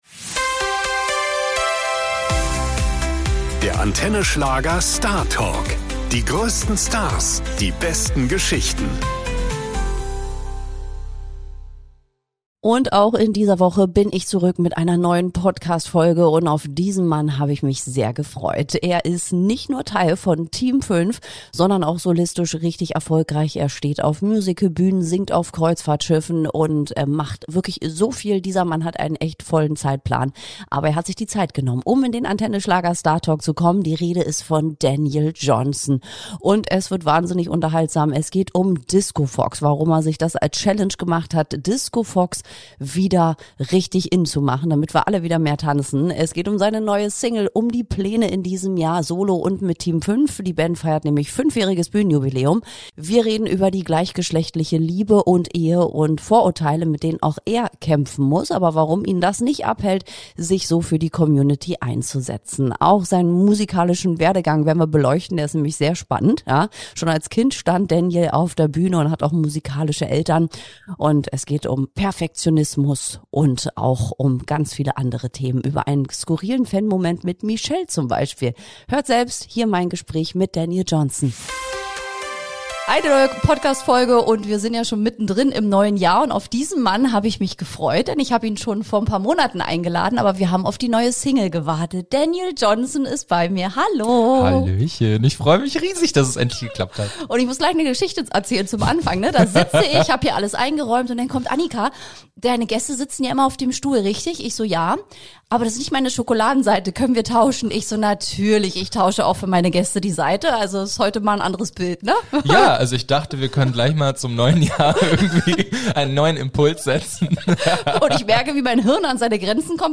Eine sehr persönliche Folge über Identität, Mut und die Kraft der Musik – emotional, ehrlich und mit jeder Menge Energie.